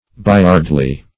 Search Result for " bayardly" : The Collaborative International Dictionary of English v.0.48: Bayardly \Bay"ard*ly\, a. Blind; stupid.